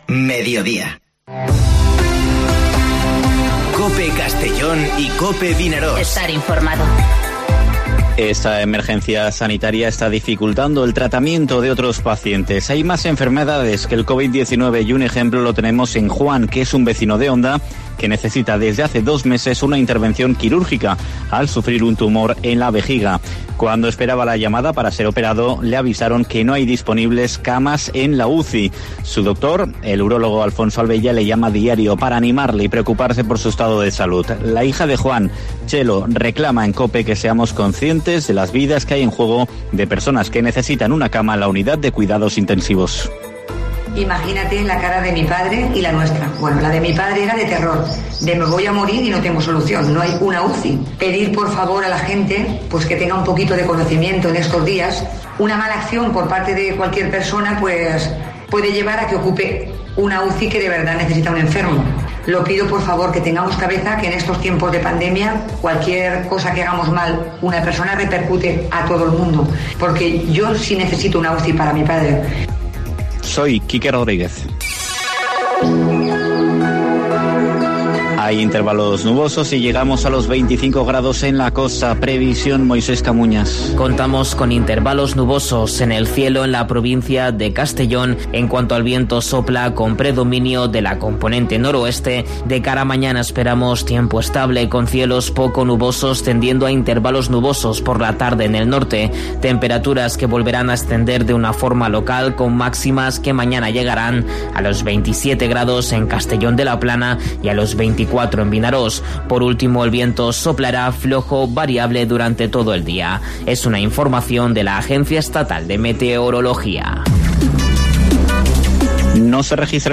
Informativo Mediodía COPE en la provincia de Castellón (30/04/2020)